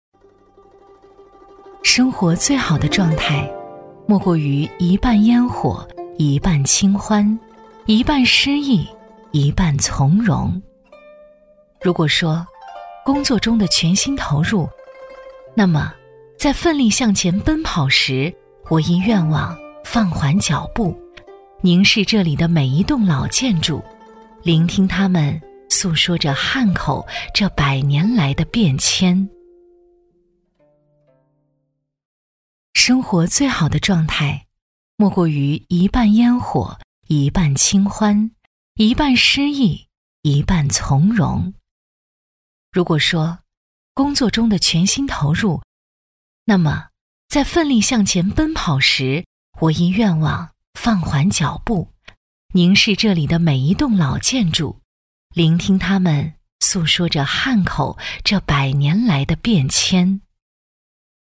• 房地产广告配音
女16-房产宣传《汉口越秀华中星汇园》-温婉 文艺
女16-房产宣传《汉口越秀华中星汇园》-温婉 文艺.mp3